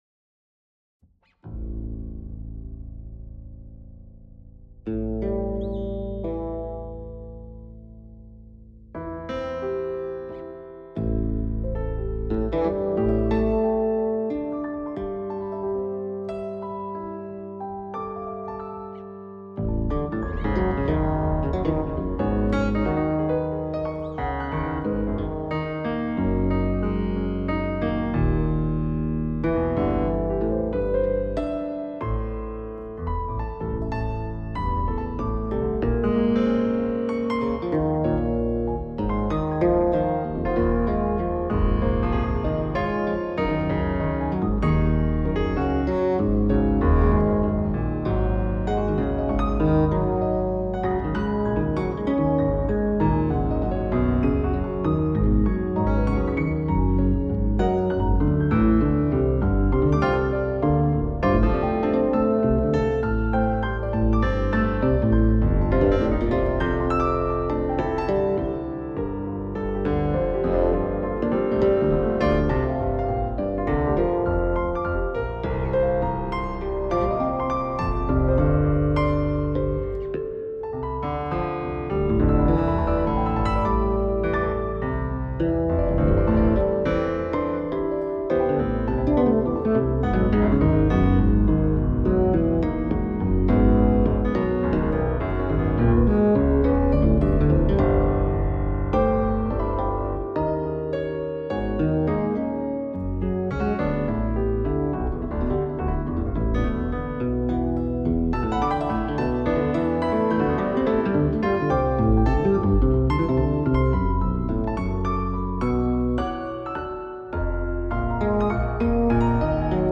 Composition pour Piano et Basse Fretless.